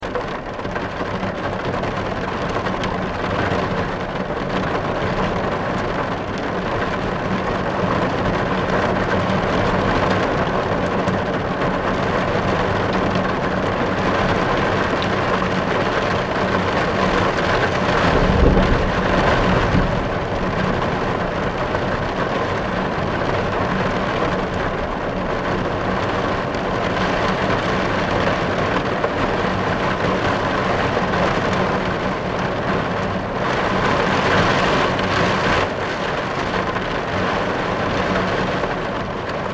جلوه های صوتی
دانلود آهنگ ماشین 8 از افکت صوتی حمل و نقل
دانلود صدای ماشین 8 از ساعد نیوز با لینک مستقیم و کیفیت بالا